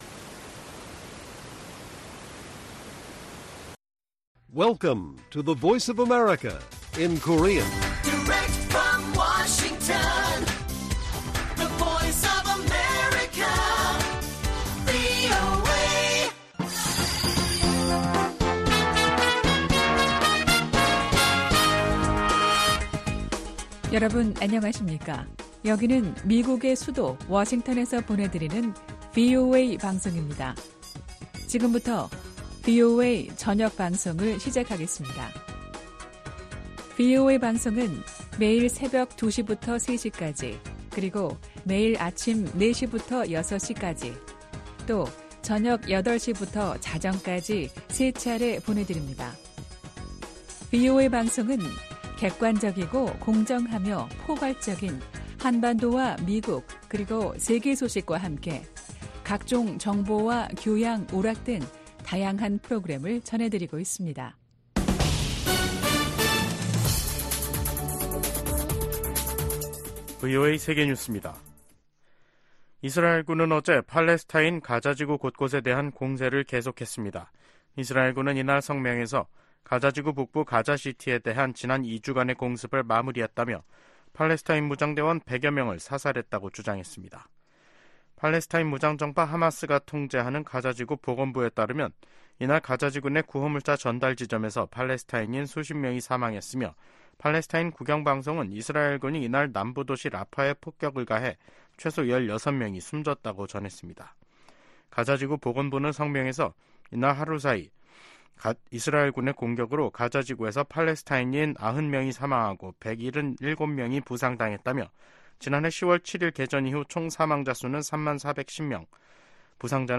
VOA 한국어 간판 뉴스 프로그램 '뉴스 투데이', 2024년 3월 4일 1부 방송입니다. 백악관 고위 관리가 한반도의 완전한 비핵화 정책 목표에 변함이 없다면서도 '중간 조치'가 있을 수 있다고 말했습니다. 유엔 안전보장이사회 순회 의장국 일본이 북한 핵 문제에 대한 국제적 대응에 나설 것이라고 밝혔습니다. 미 상원 중진의원이 중국 수산물 공장의 북한 강제 노동 이용은 현대판 노예 범죄라며, 관련 상품 수입 금지를 조 바이든 행정부에 촉구했습니다.